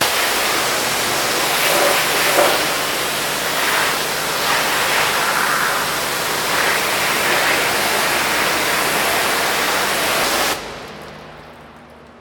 hose.ogg